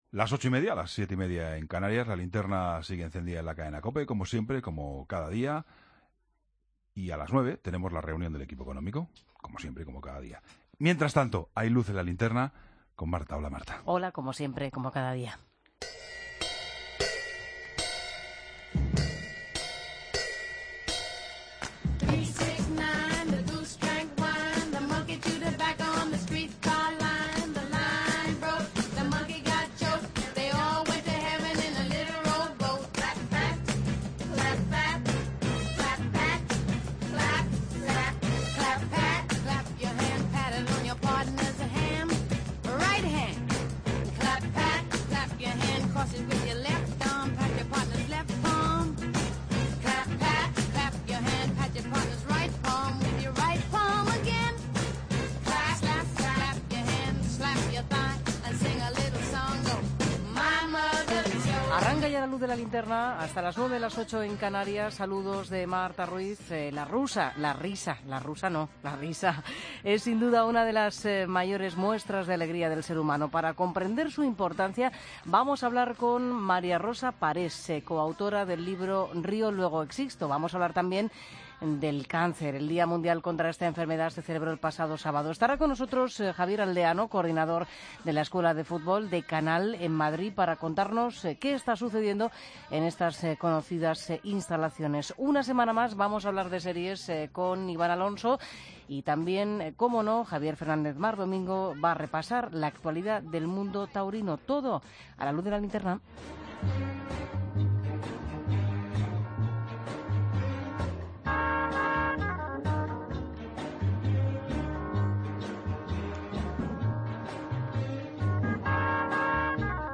Entrevista
Reportaje